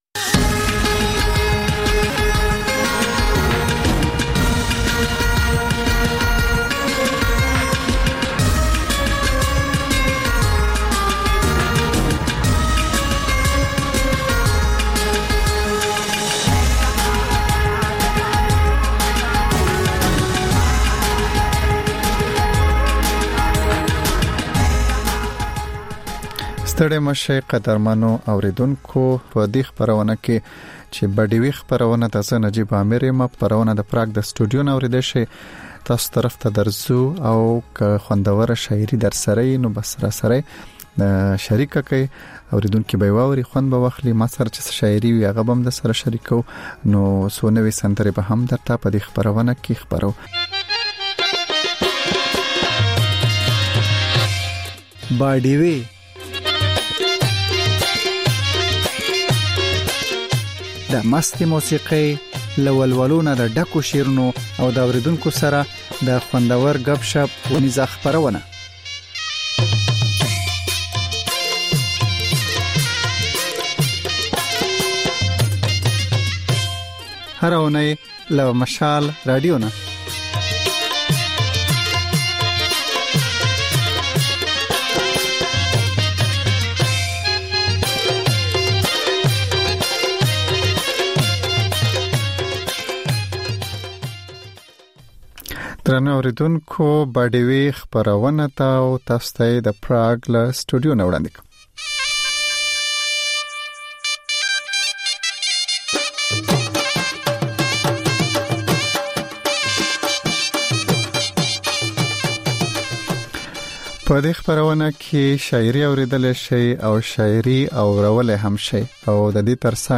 په دې خپرونه کې تر خبرونو وروسته بېلا بېل رپورټونه، شننې، مرکې خپرېږي.